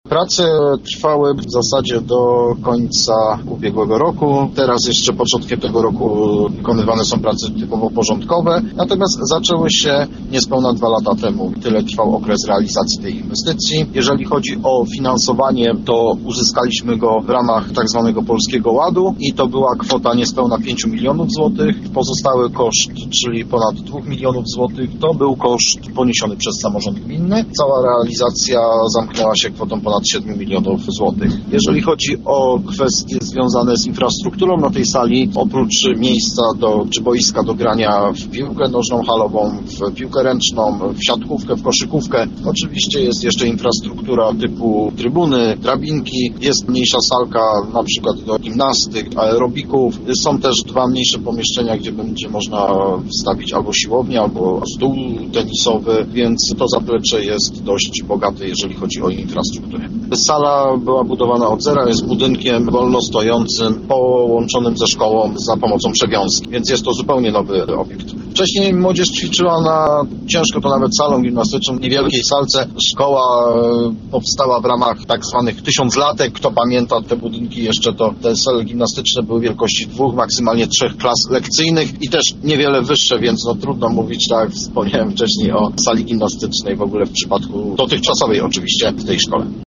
O nowej sali opowiedział nam wójt Artur Beniowski.